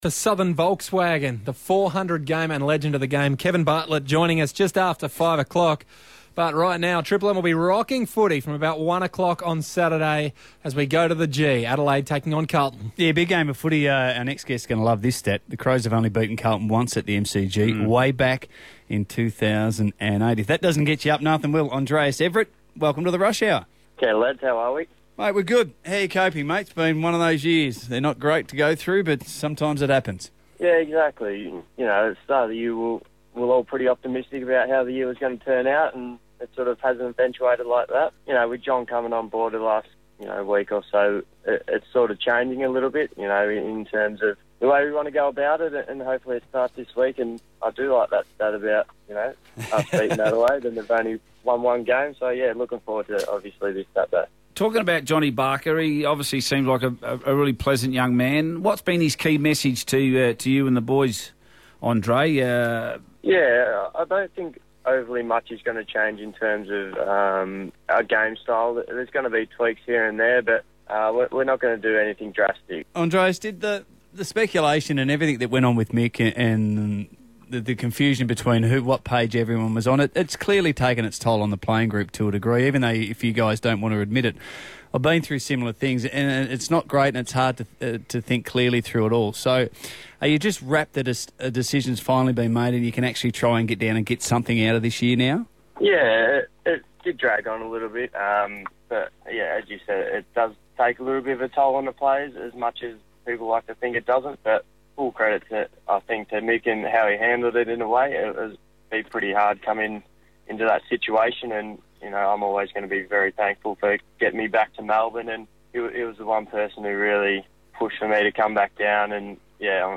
Carlton utility Andrejs Everitt speaks to Triple M Adelaide ahead of the Blues' clash with the Crows.